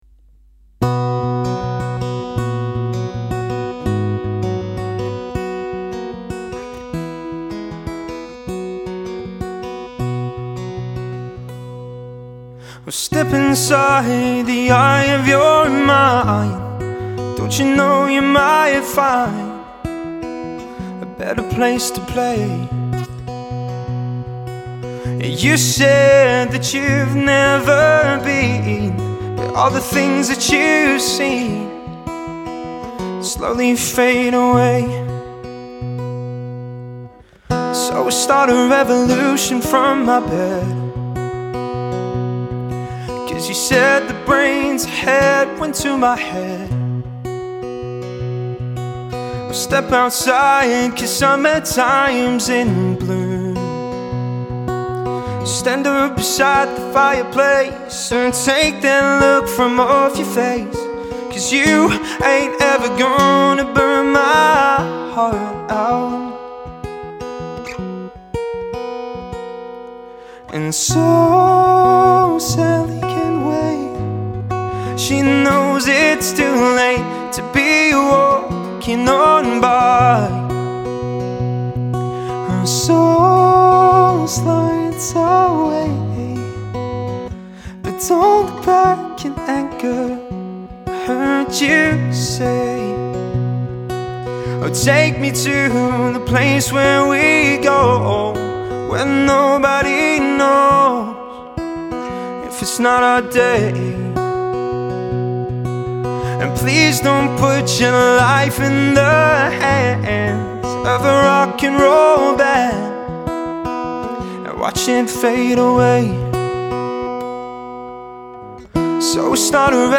Vocals | Guitar | Looping | DJ | MC